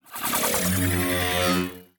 Robotic Notification 3.wav